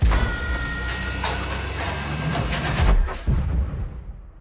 push.ogg